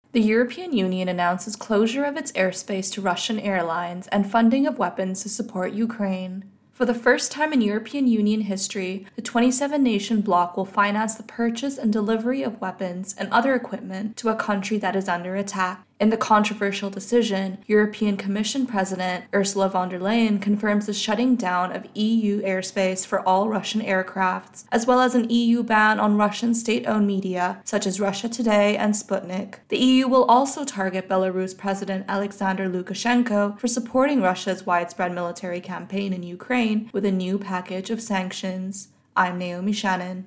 Intro and Voicer for Ukraine-Invasion-Global Reaction